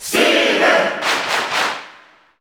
Steve_Cheer_Spanish_SSBU.ogg